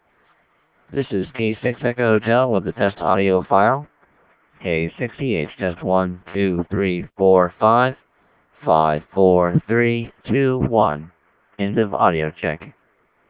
COMPUTER BASED, FREE SOFTWARE WHICH UTILIZES YOUR COMPUTER SOUND CARD.
FDMDV-decoded.wav